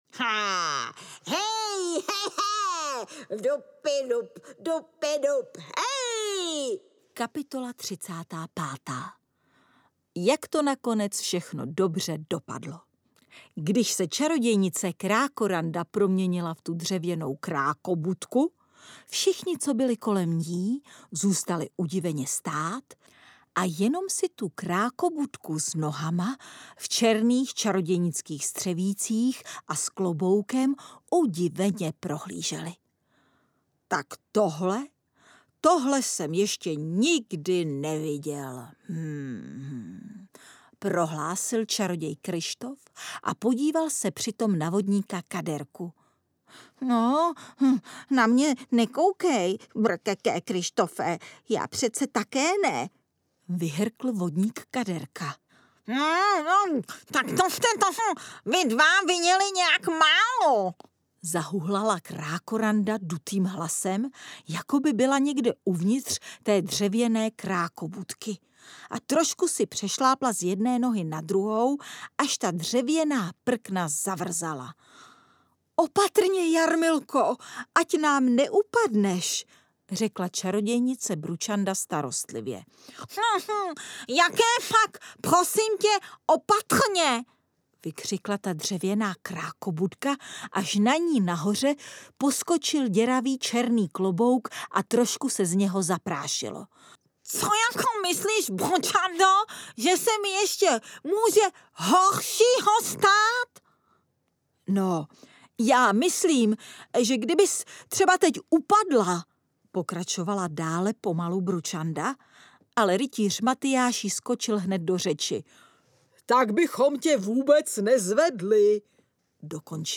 Strašidelný kongres 2 audiokniha
Ukázka z knihy
Příběhy opět provází báječný herecký koncert Nely Boudové.
• InterpretNela Boudová